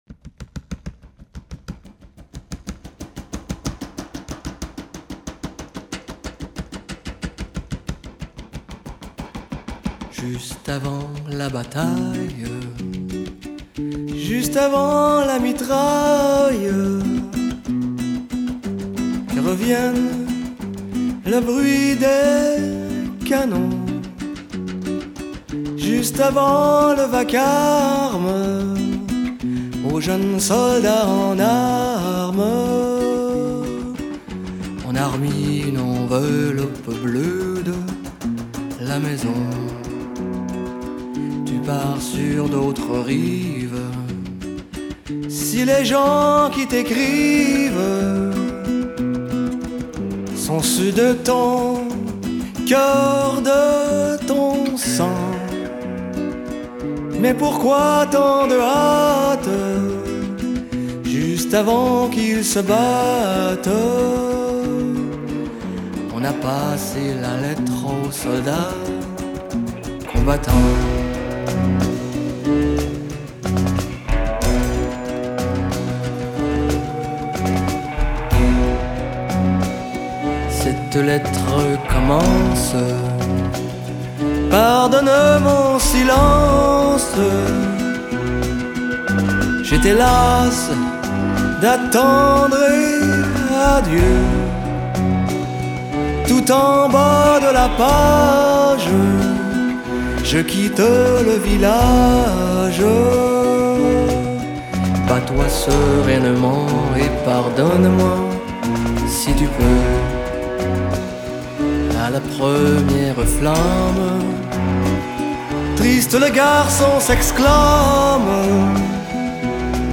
контрабас
перкуссия
аккордеон и скрипка
фортепиано